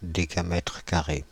Ääntäminen
Synonyymit are Ääntäminen France (Île-de-France): IPA: /de.ka.mɛtʁ ka.ʁe/ Haettu sana löytyi näillä lähdekielillä: ranska Käännöksiä ei löytynyt valitulle kohdekielelle.